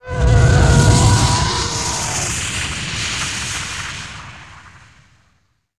roar3.wav